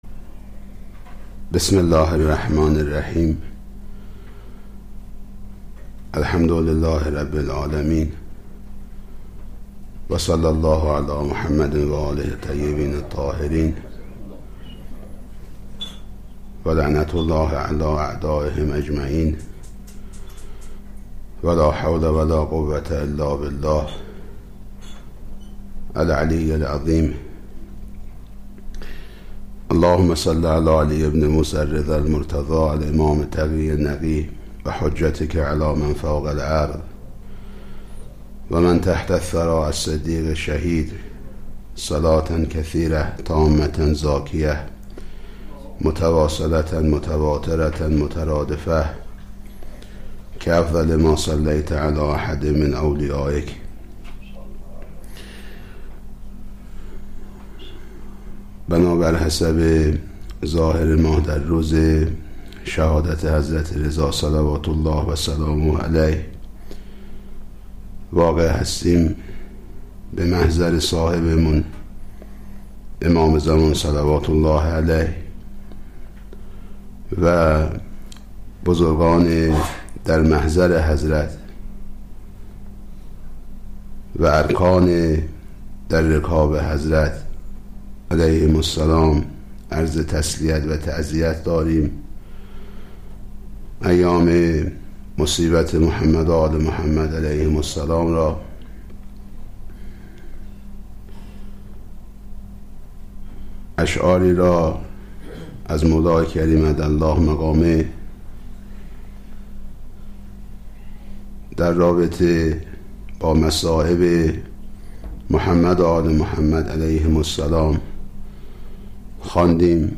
دسته بندی : مجالس روضه و مدح آل الله الاطهار علیهم صلوات الله الملک الجبار